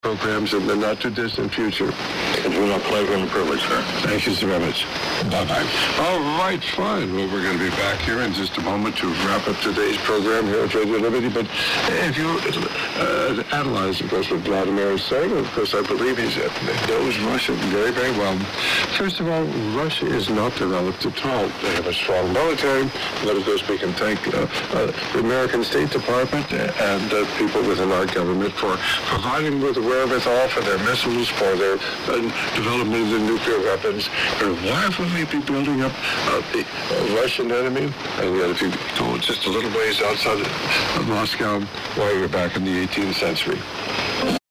Sound bytes - Here are 3 audio snippets recorded on a Zoom H4 professional digital recorder of the same frequency over a period of a couple of minutes using the Eton E100, the Eton E1 and the Sony 2010:
The Sony 2010 was in narrow -- which is slightly less that 2 khz (with Sync lock in) -- The Eton E100 fairs quite well without Sync detect - and the overall sensitivity is no slouch.